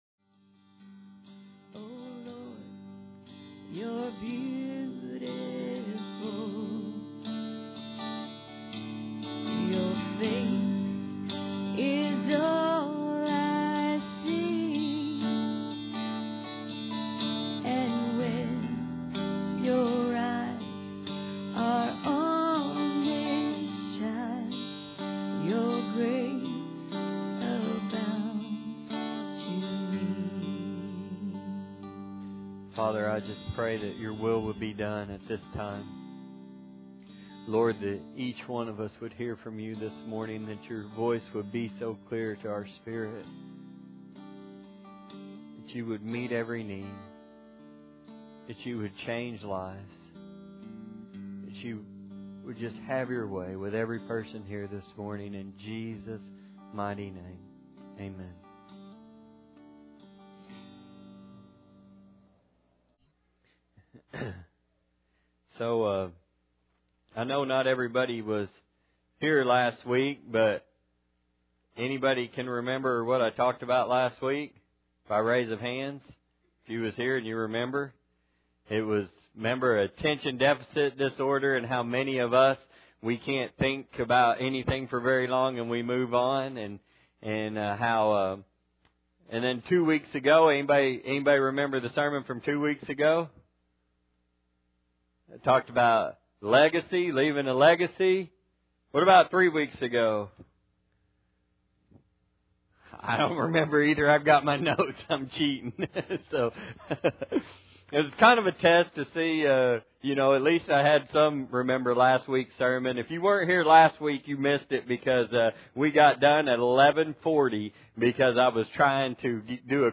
Galations 5:44 Service Type: Sunday Morning Bible Text